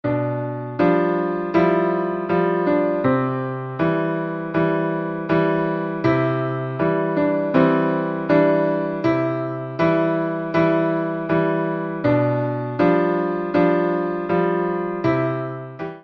「ドミソ」と「シファソ」のみのシンプルな左手です。